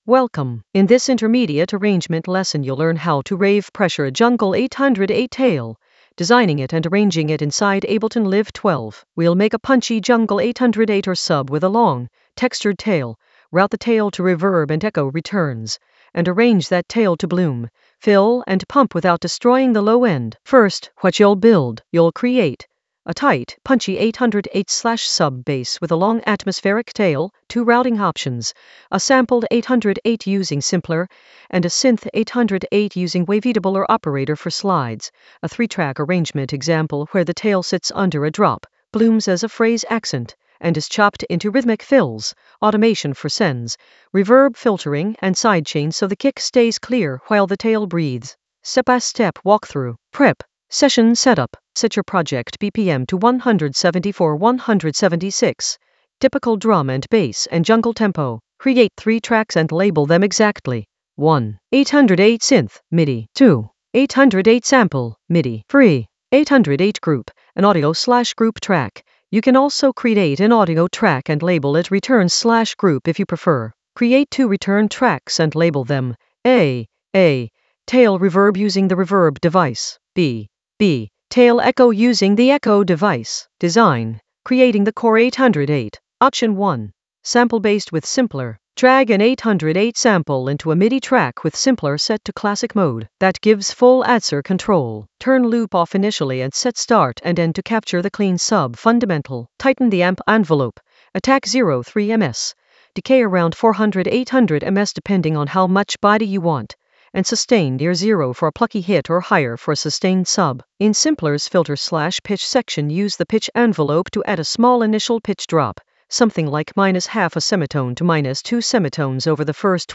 An AI-generated intermediate Ableton lesson focused on Rave Pressure a jungle 808 tail: design and arrange in Ableton Live 12 in the Arrangement area of drum and bass production.
Narrated lesson audio
The voice track includes the tutorial plus extra teacher commentary.